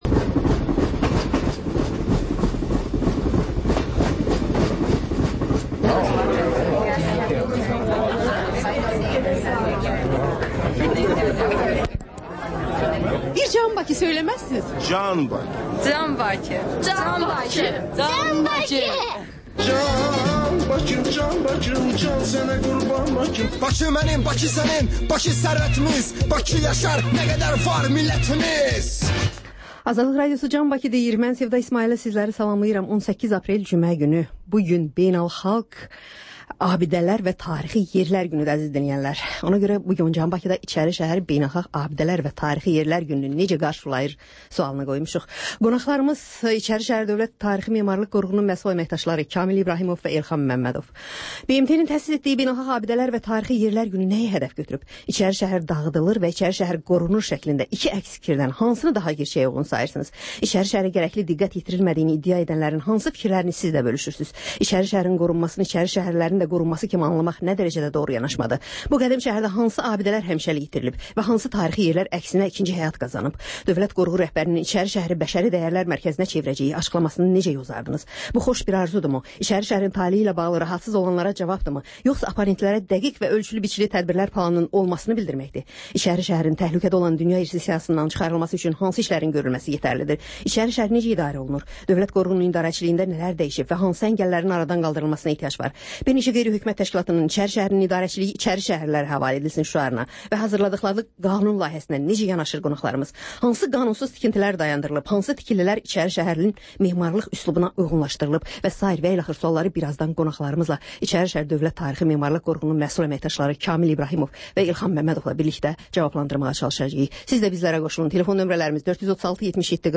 Xəbərlər, sonra CAN BAKI verilişi: Bakının ictimai və mədəni yaşamı, düşüncə və əyləncə həyatı…